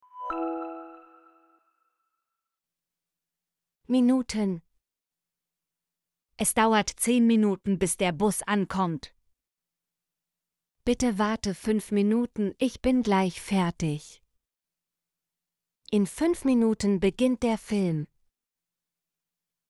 minuten - Example Sentences & Pronunciation, German Frequency List